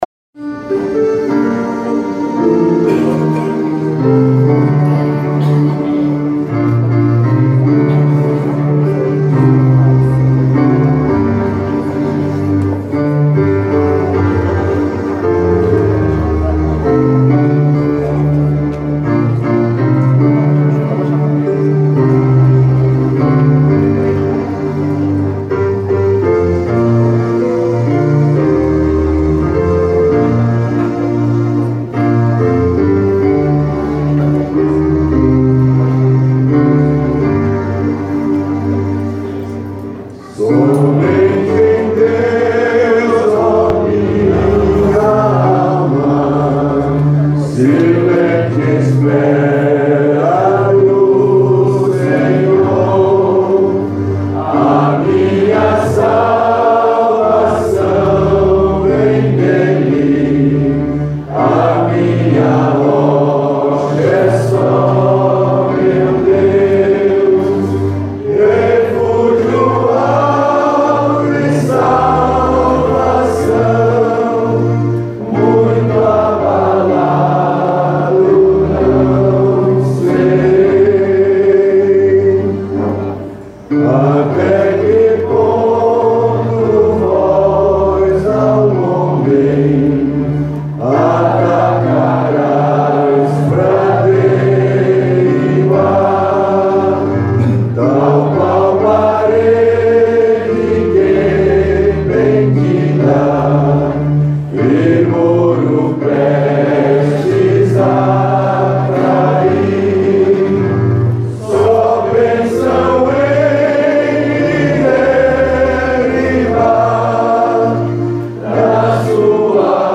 Métrica: 9. 8. 9. 8. 8. 8
salmo_62B_cantado.mp3